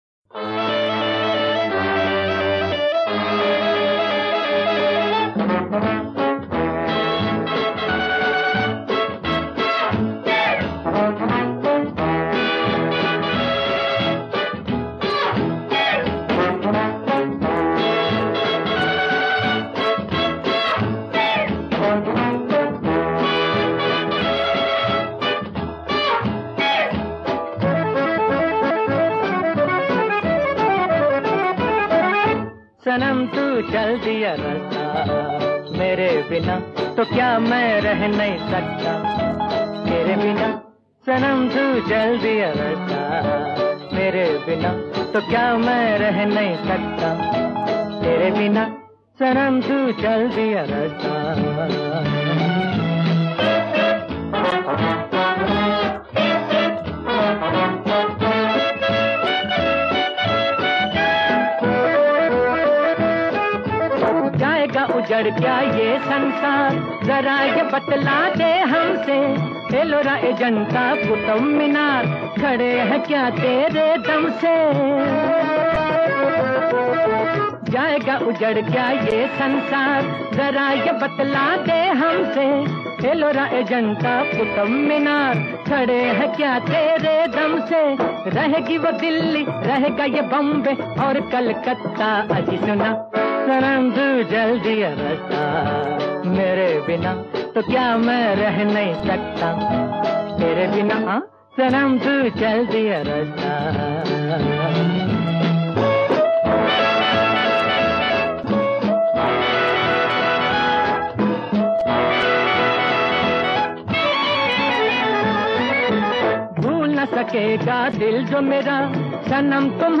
Bollywood Songs